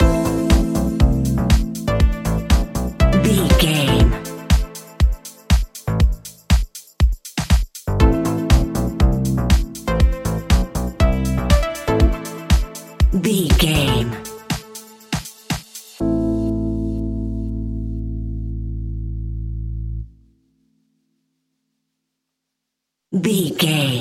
Aeolian/Minor
groovy
uplifting
driving
energetic
drums
bass guitar
synthesiser
electric piano
funky house
electro
disco
upbeat
instrumentals